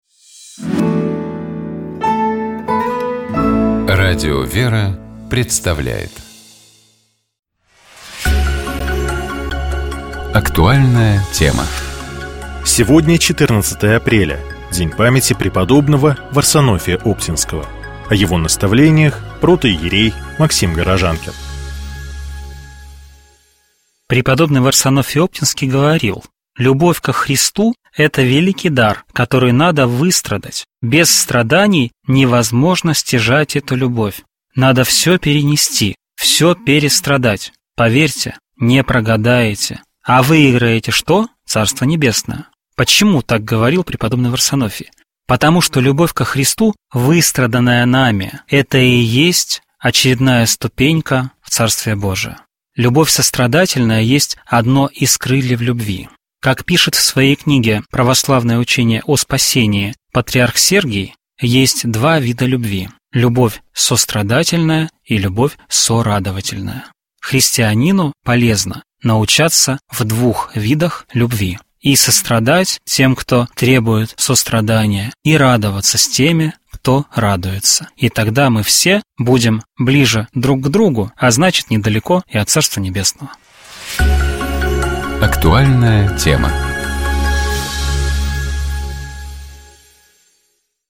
протоиерей